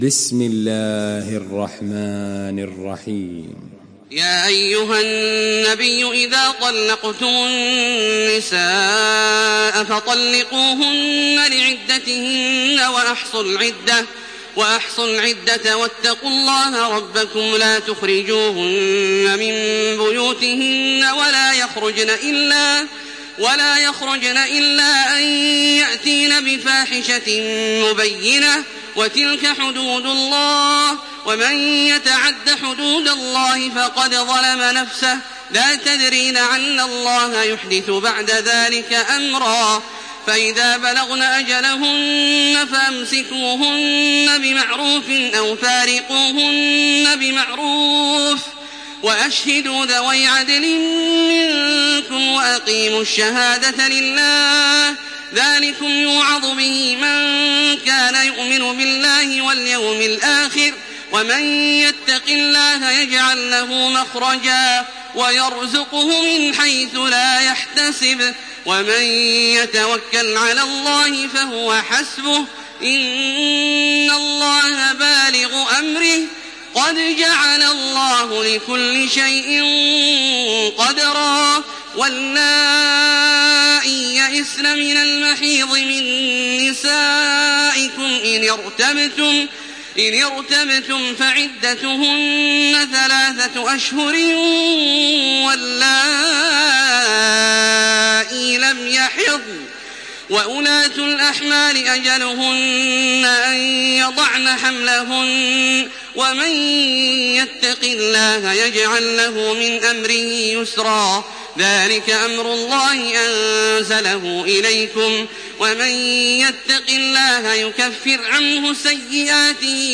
Surah At-Talaq MP3 by Makkah Taraweeh 1428 in Hafs An Asim narration.
Murattal